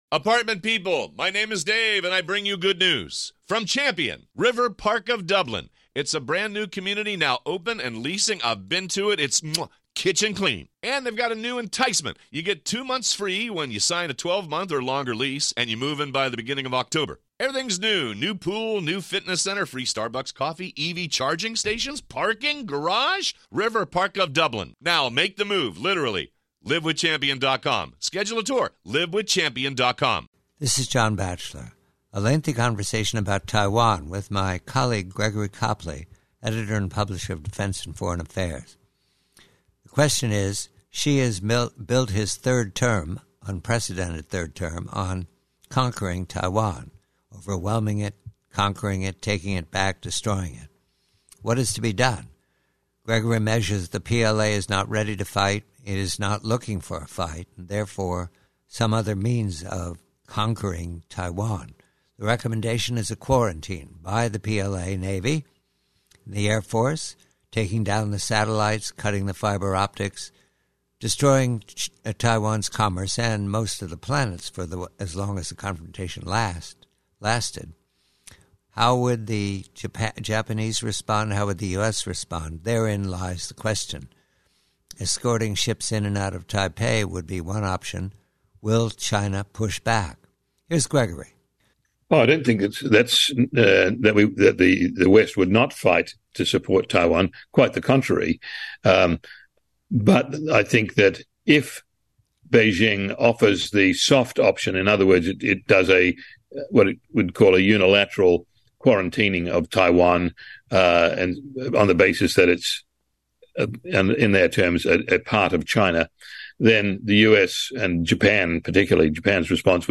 PREVIEW: From a longer conversation